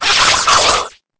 Cri_0852_EB.ogg